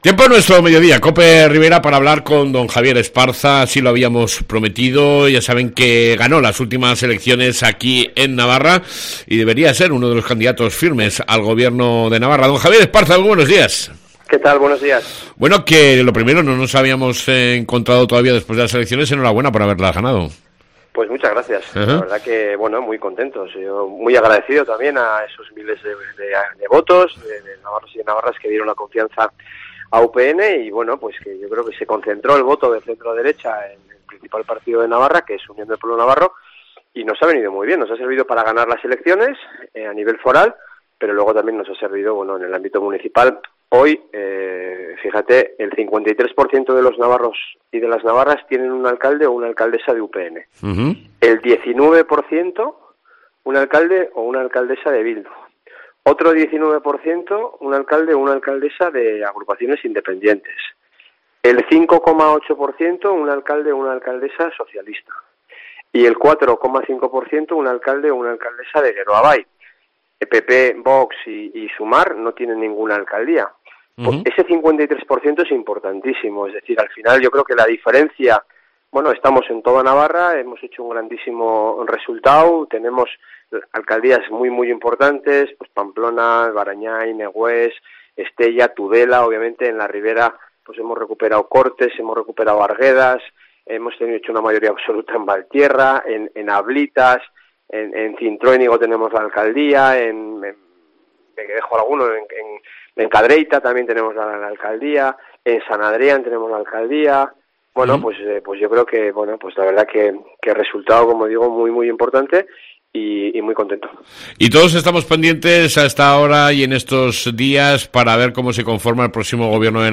ENTREVISTA CON EL PRESIDENTE DE UPN , JAVIER ESPARZA